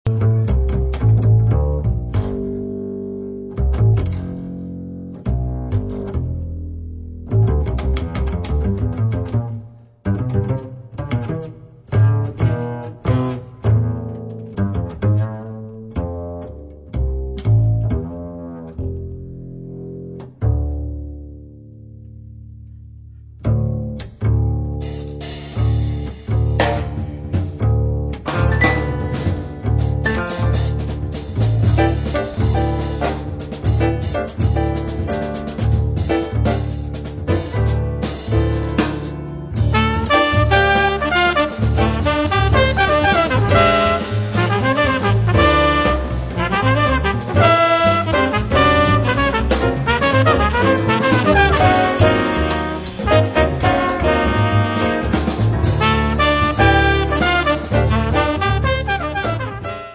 piano
basso
batteria
sax tenore
Tromba